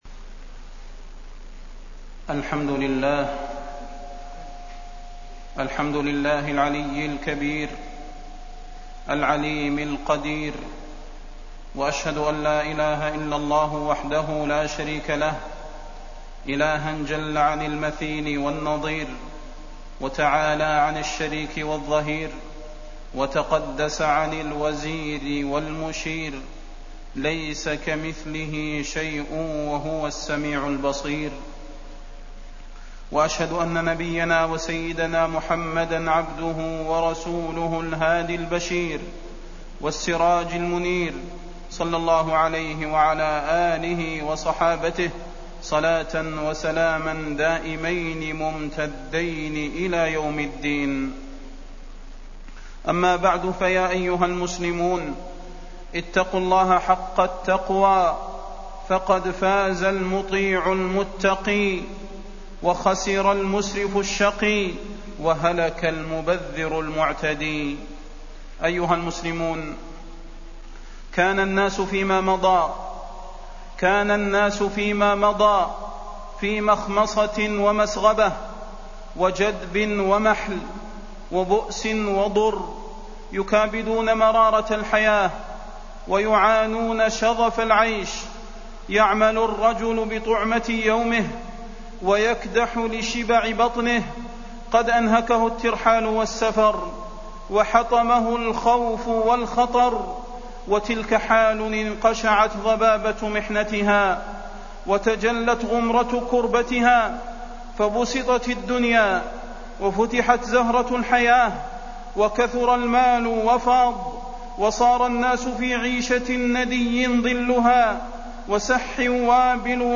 تاريخ النشر ٤ شعبان ١٤٢٨ هـ المكان: المسجد النبوي الشيخ: فضيلة الشيخ د. صلاح بن محمد البدير فضيلة الشيخ د. صلاح بن محمد البدير بطر النعمة